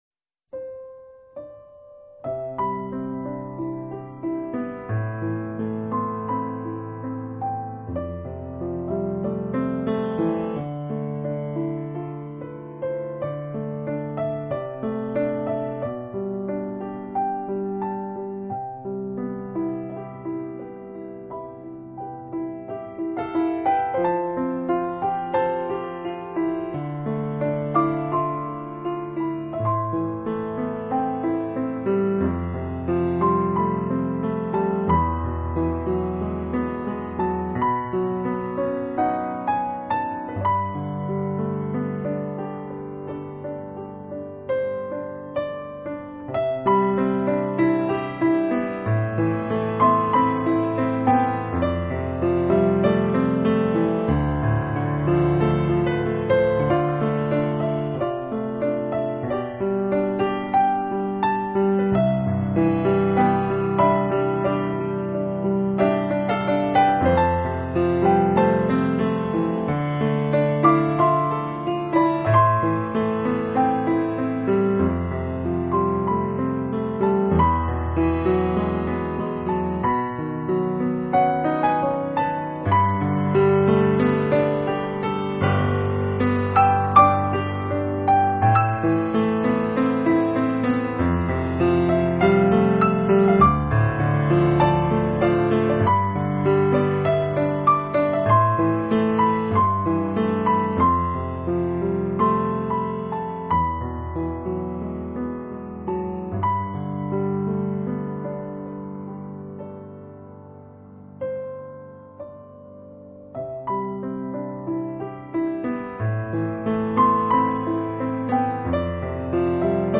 【新世纪钢琴】
Genre: Neo - Classical